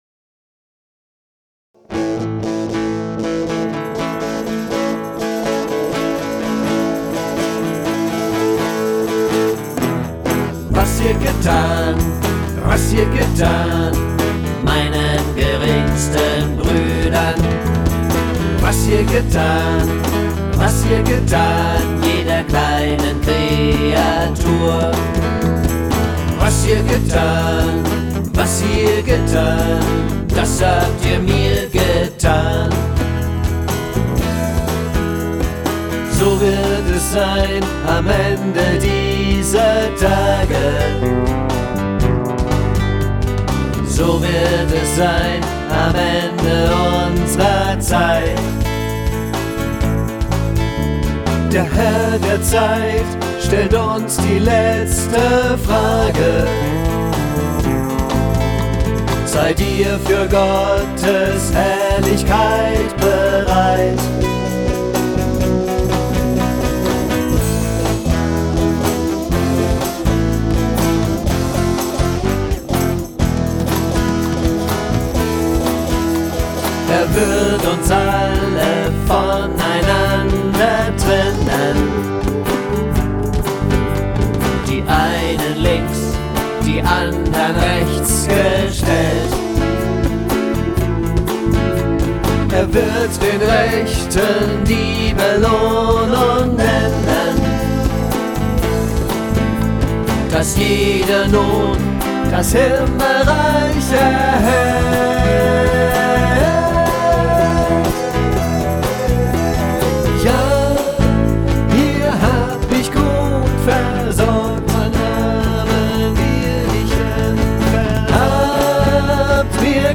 akustik-version